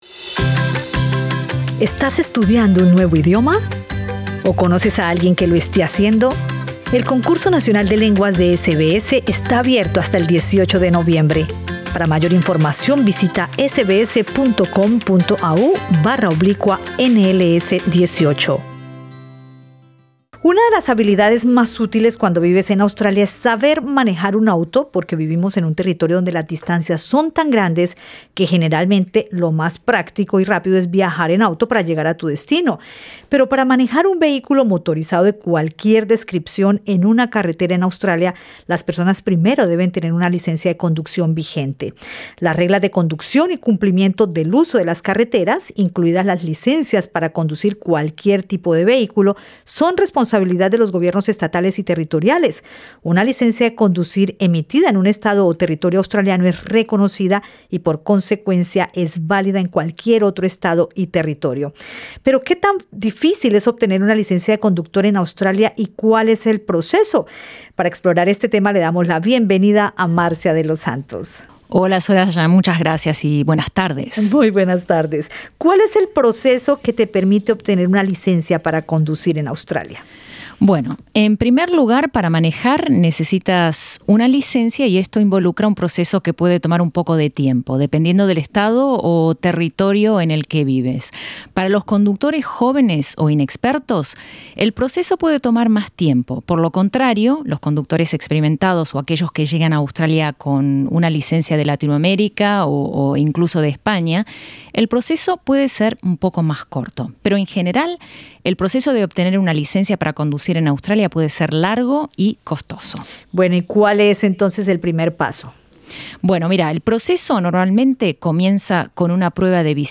Escucha el informe de SBS Radio en español para enterarte cómo obtener una licencia para conducir un vehículo en Australia.